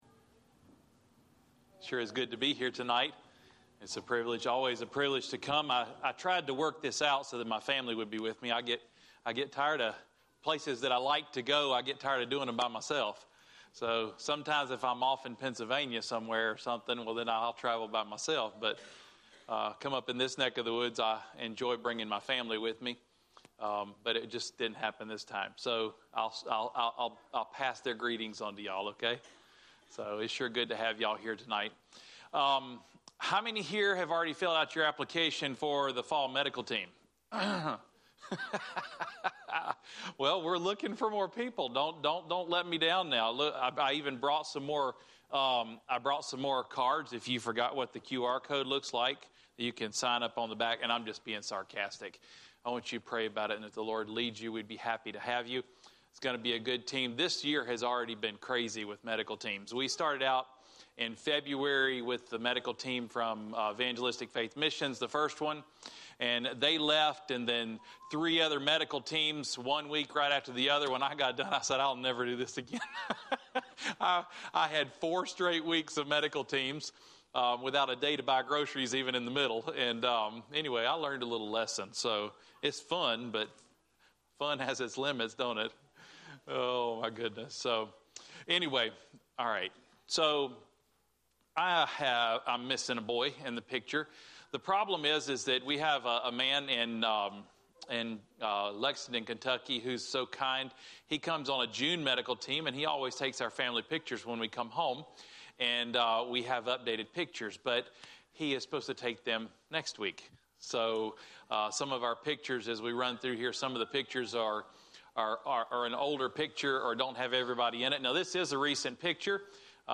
Missionary Service